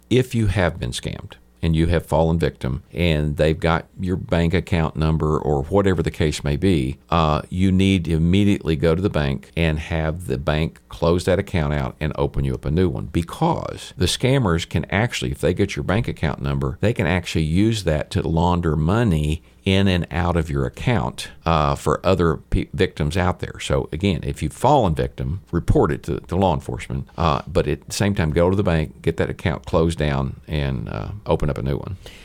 In response to a surge in scams targeting Baxter County residents, KTLO hosted Sheriff John Montgomery Thursday morning for a live call-in program aimed at educating the public on how to avoid common scams.